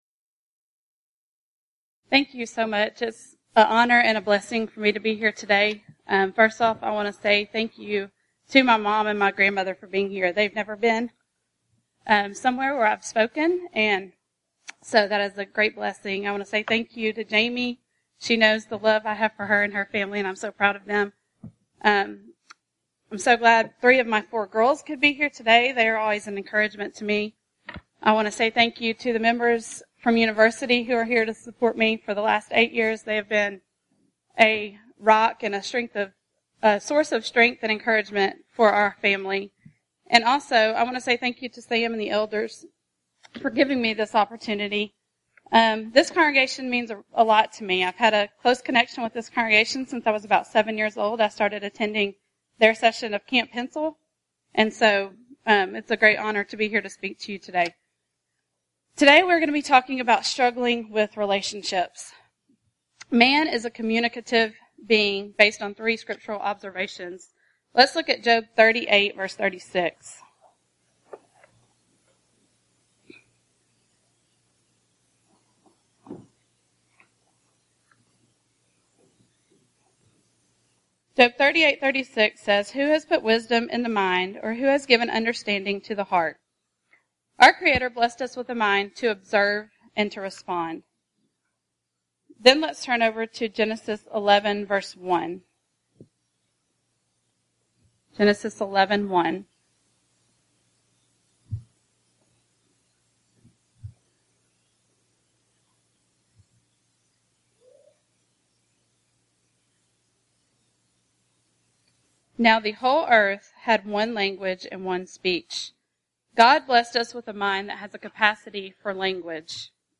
Event: 34th Annual Southwest Lectures Theme/Title: God's Help with Life's Struggles
Ladies Sessions